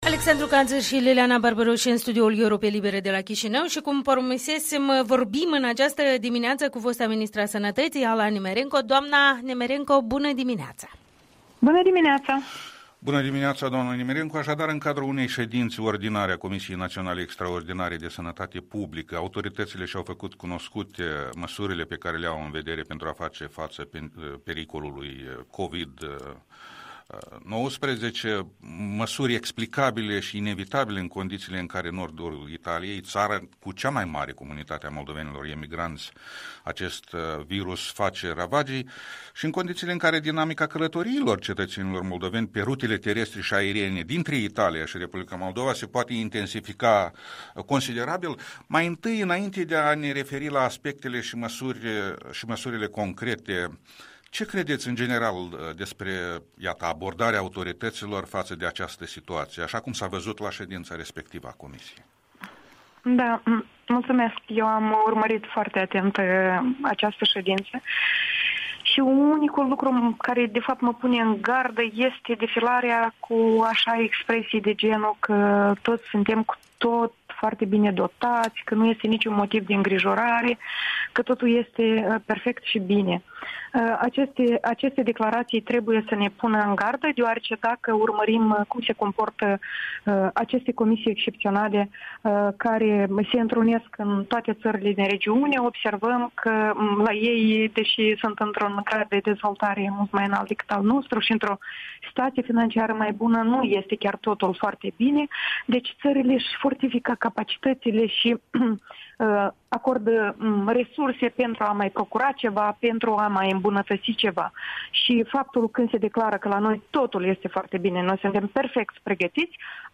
Interviu cu fosta ministră a sănătății, Ala Nemerenco